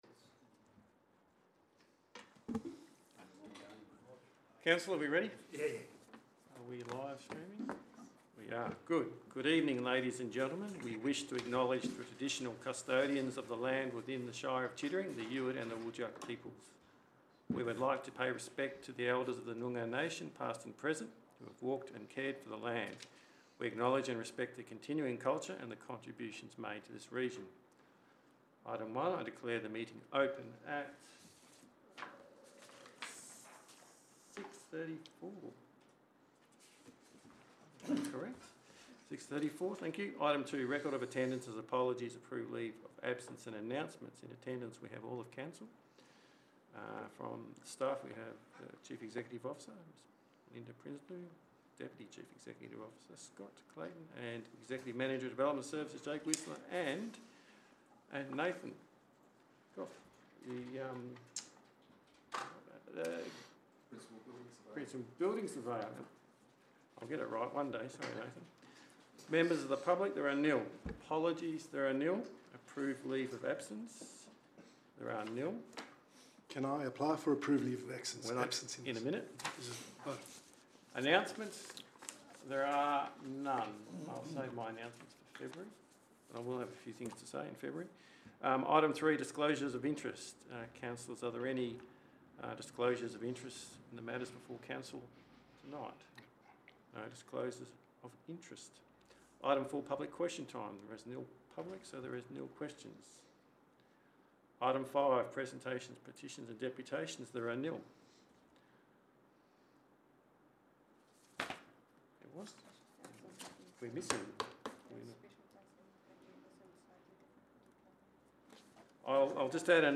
January Special Council Meeting » Shire of Chittering